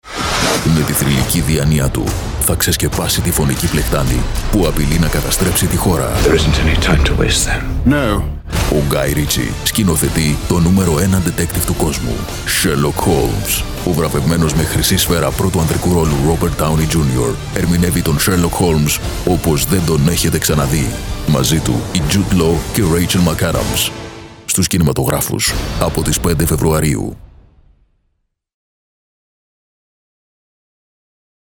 Professional Voice Artist and Voice Coach.
Sprechprobe: Werbung (Muttersprache):